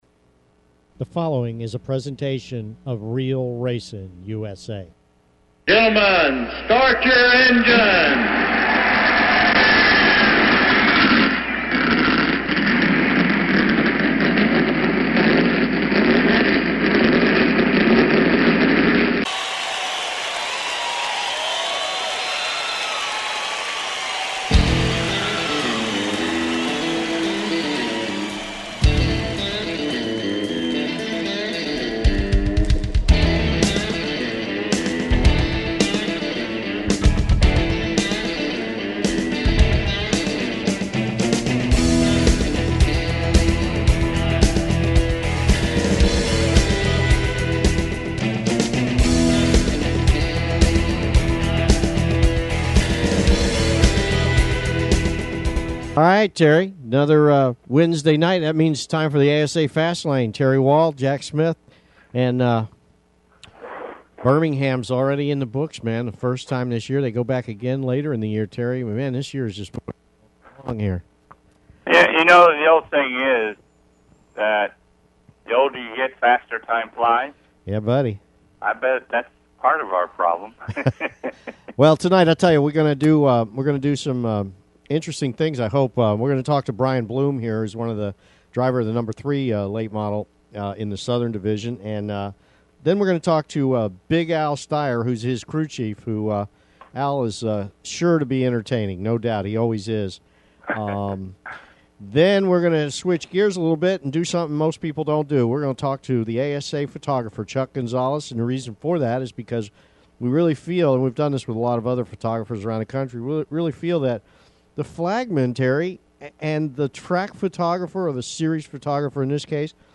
The “ASA Fastlane” returns to its regularly scheduled time this week, Wednesday 8-9 PM, with a interesting mix of news and interviews.